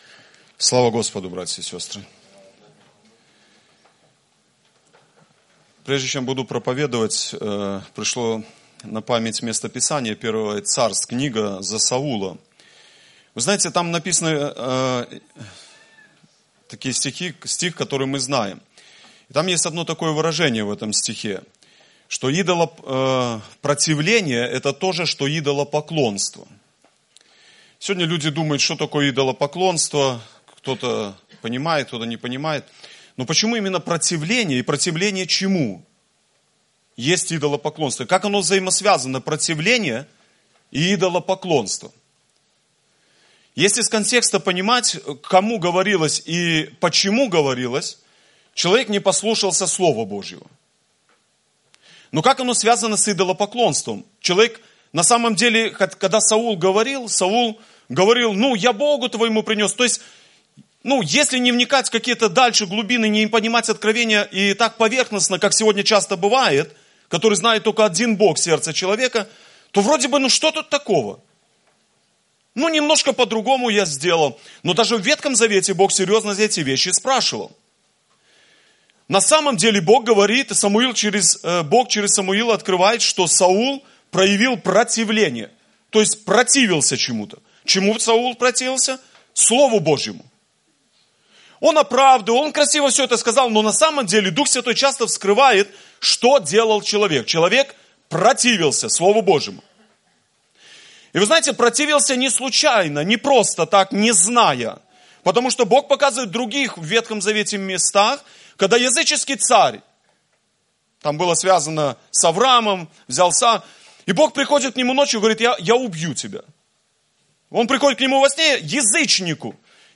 Гефсимания - Проповеди